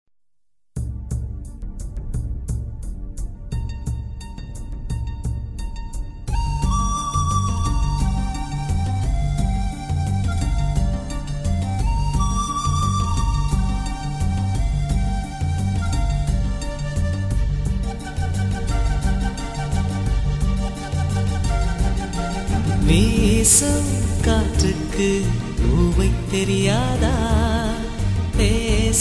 best flute ringtone download
melody ringtone romantic ringtone